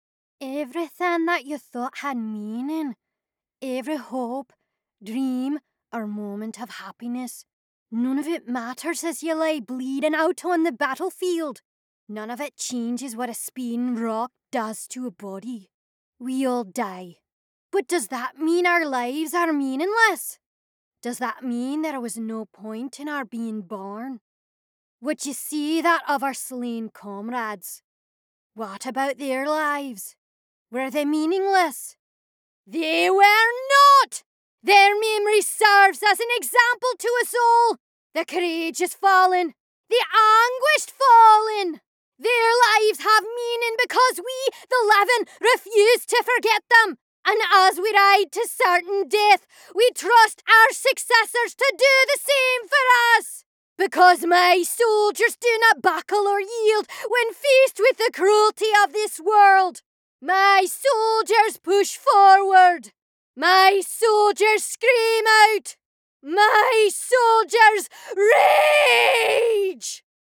I have a wonderfully weird, bouncy voice that has inspired many a collaborator to think about their characters in entirely new lights.
scottish | character